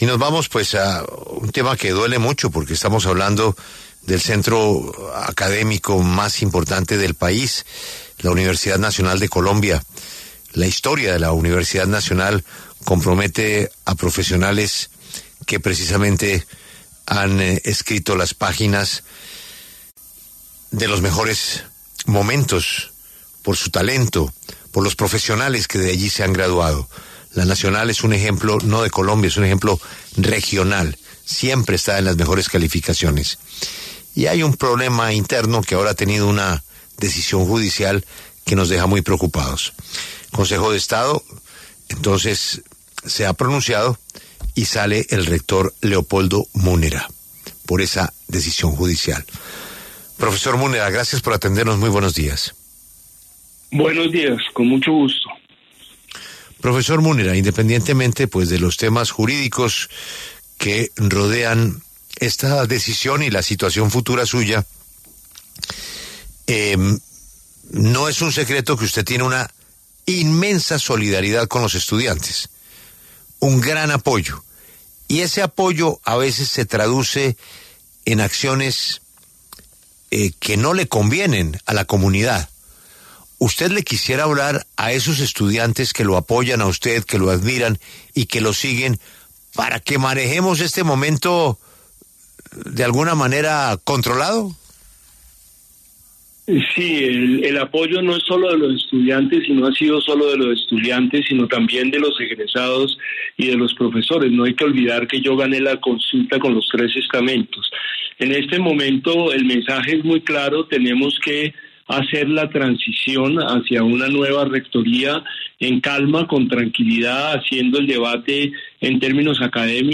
En esa línea, este viernes, 21 de noviembre, el mismo Leopoldo Múnera habló en La W, con Julio Sánchez Cristo, y reveló en primicia que presentará su renuncia para facilitar una transición.